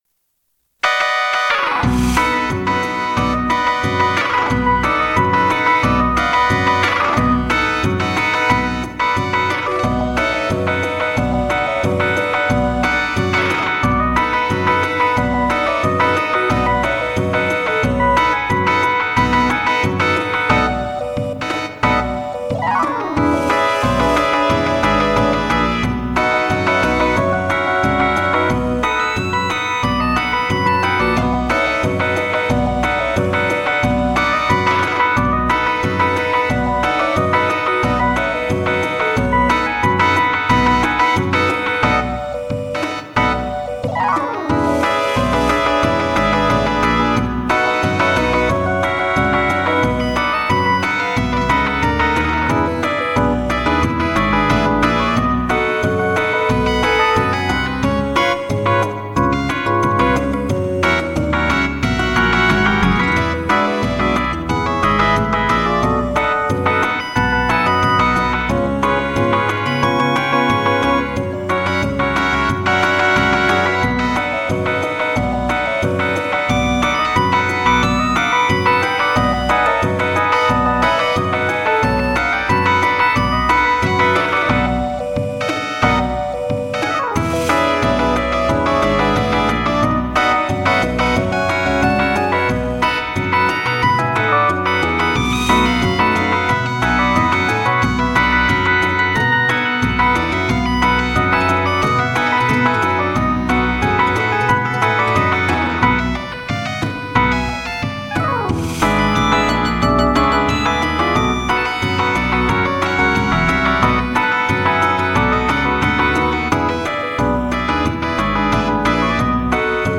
Записана во время концерта на крыше.